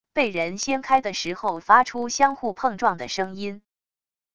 被人掀开的时候发出相互碰撞的声音wav音频